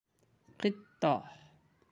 (qittah)